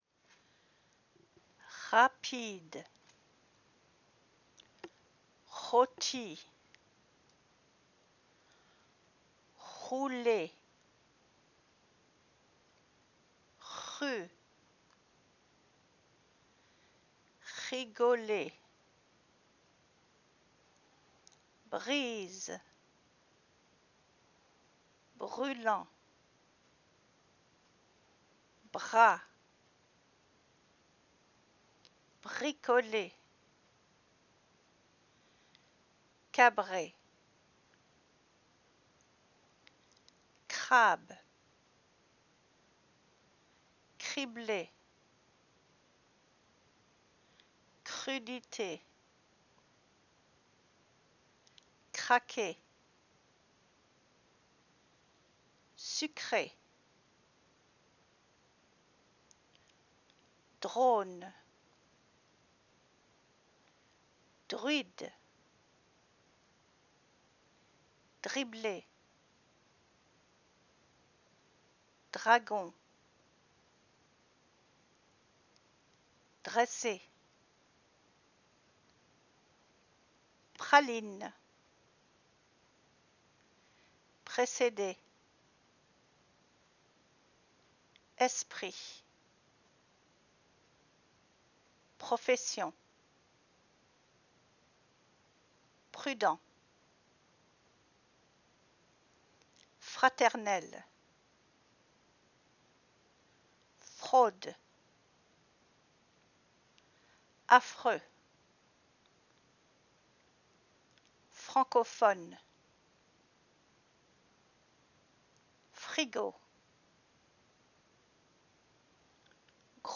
Pronunciation – The Letter ‘r’
Répétez (repeat) chaque mot.